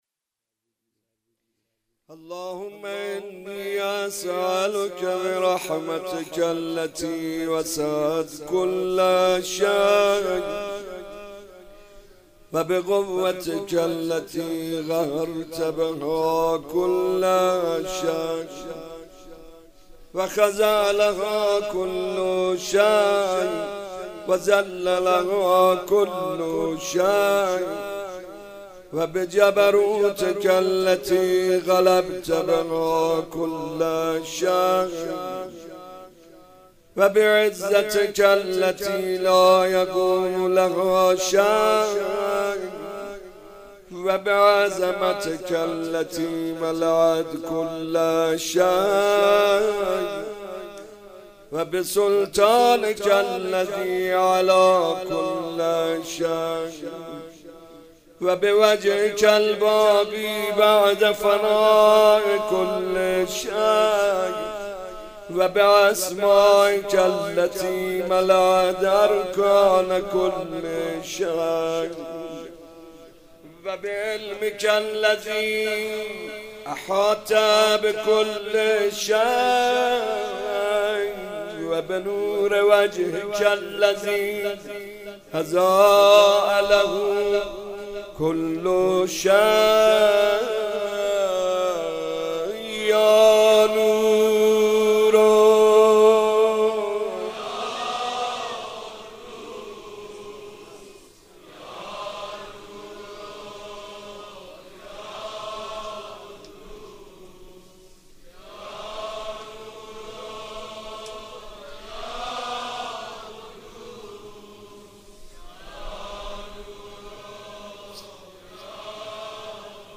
دعای کمیل مناسبت : شب بیست و یکم رمضان - شب قدر دوم سال انتشار
قالب : مناجات